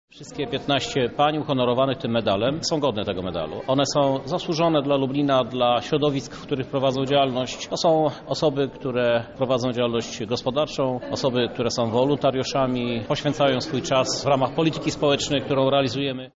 Myślę, że jest to idea godna święta kobiet i warta kontynuowania – mówi prezydent miasta Lublin